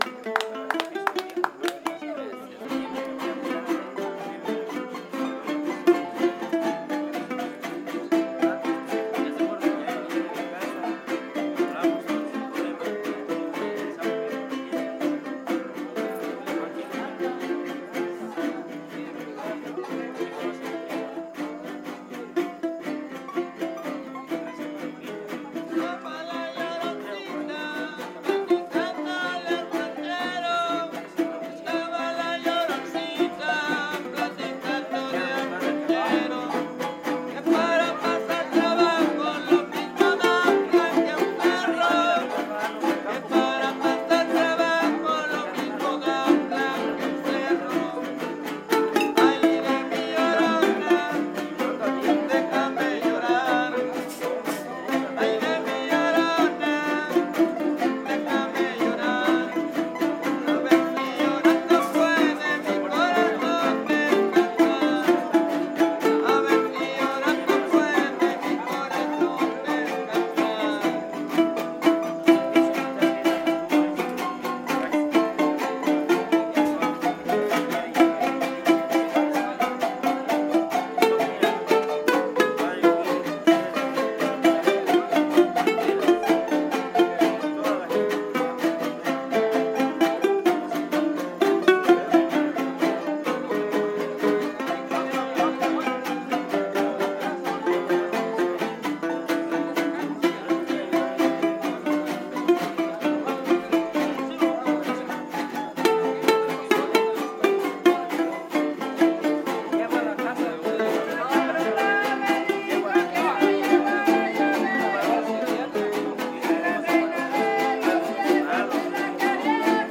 Fiesta Patronal de San Antonio de Padua